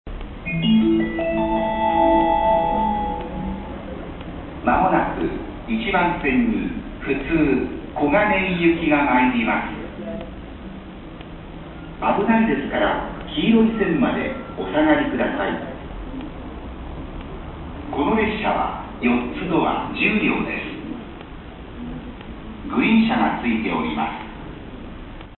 接近放送普通小金井行き4ドア10両
普通小金井行き4ドア10両の接近放送です。